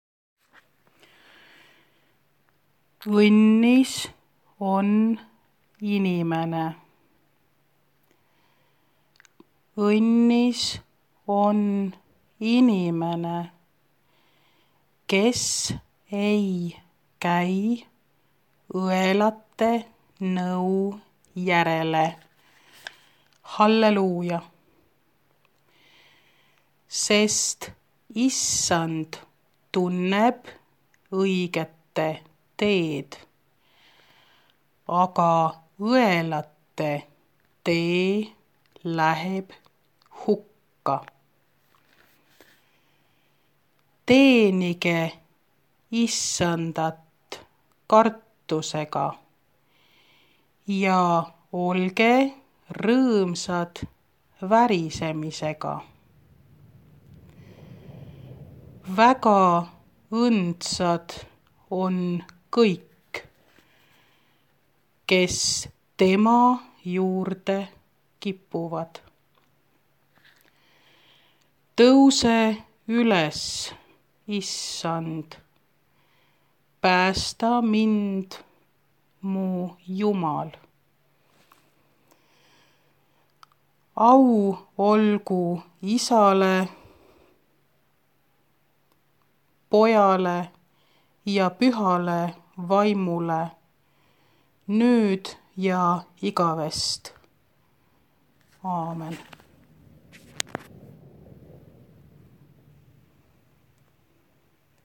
Genre-Style-Form : Sacred ; Motet ; Psalm
Mood of the piece : moderate
Type of choir : SSAATTBB (8 mixed voices )
Tonality : D dorian
Keywords: a cappella ; bad ; righteous ; adoration ; Lord ; man ; happy ; praise to God ; Glory of God
sung by the chamber choir of St. John's Church of Tartu